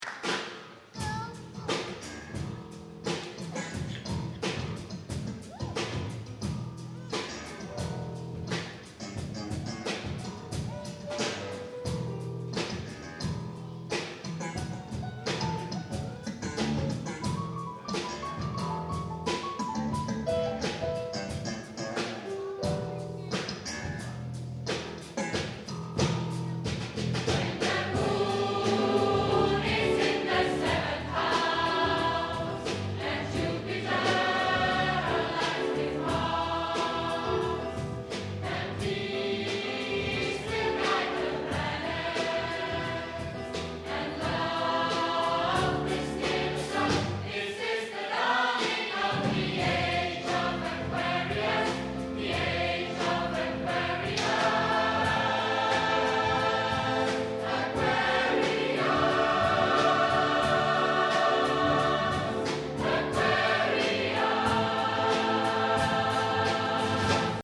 Live-Aufnahmen Konzert Schneisingen 2007
Live-Konzert vom 20./21. Januar 2007 in der kath. Kirche Schneisingen.
Aquarius Chor